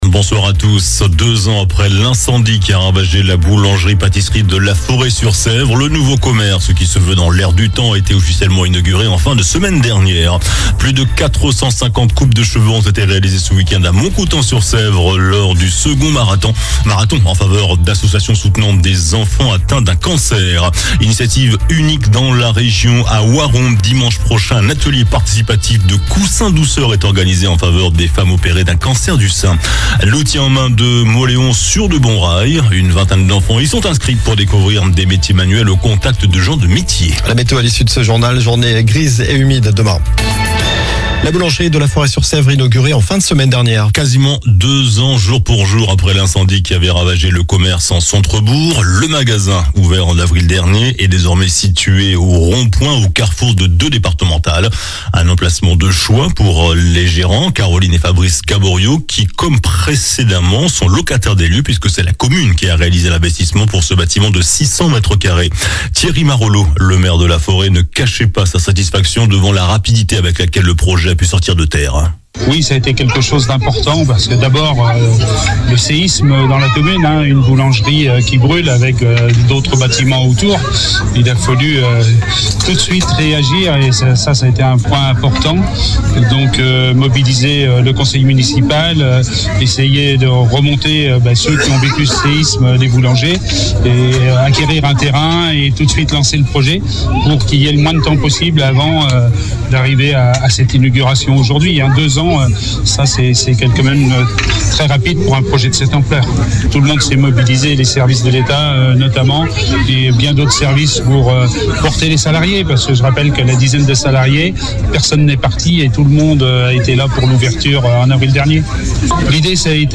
JOURNAL DU LUNDI 30 SEPTEMBRE ( SOIR )